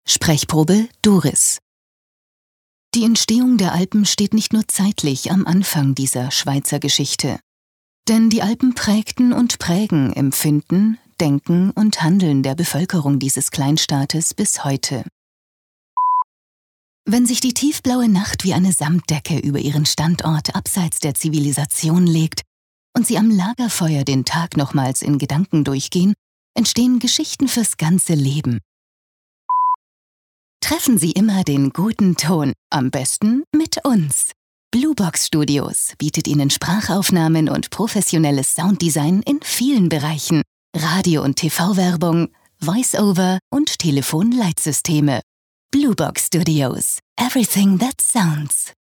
OFF-Kommentar Hochdeutsch (DE)
Sprecherin.